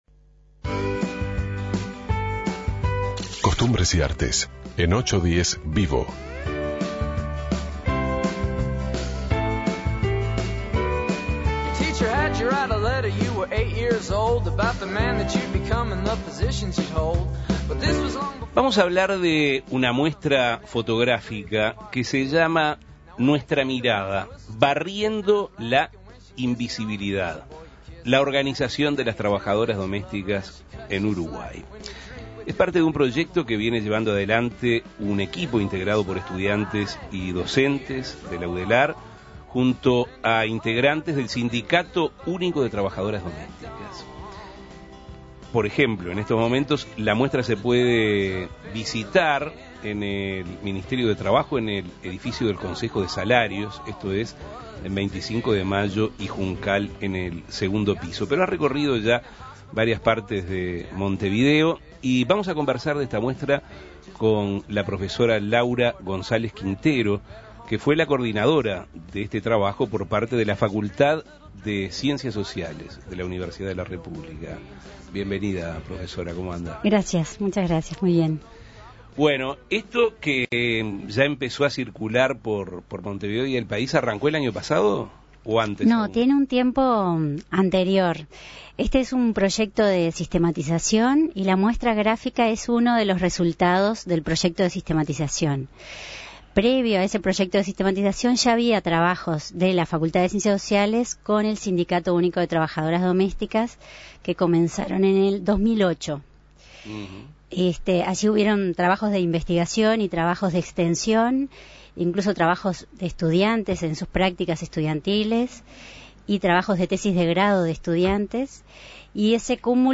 Hoy en 810 VIVO Avances, tendencias y actualidad recibimos en estudios a la profesora